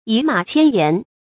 注音：ㄧˇ ㄇㄚˇ ㄑㄧㄢ ㄧㄢˊ
倚馬千言的讀法